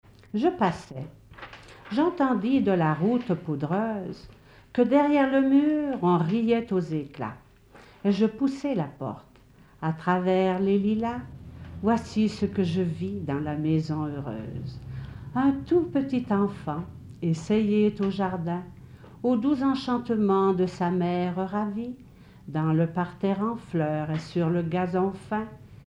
Poème
Catégorie Témoignage